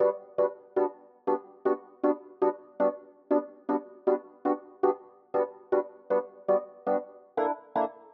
12 ElPiano PT4.wav